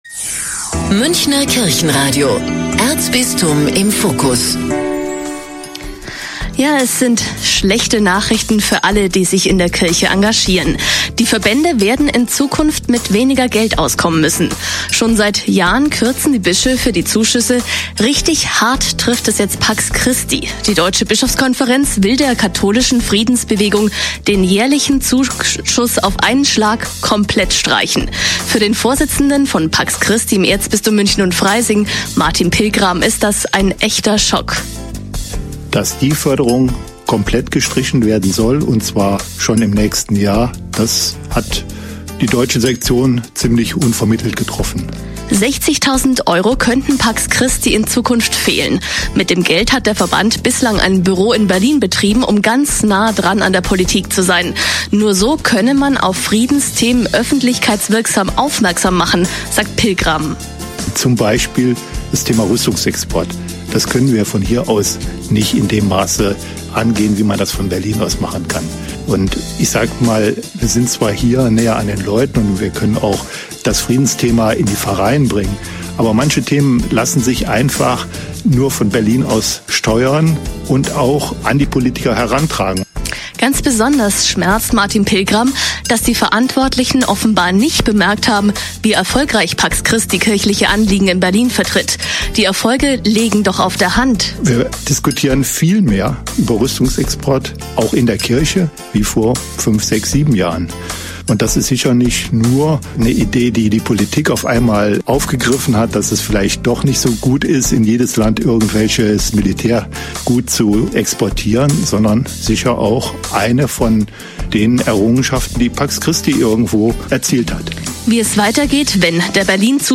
Münchner Kirchenradio - Hintergrund - Zur Streichung des pax christi Zuschusses.mp3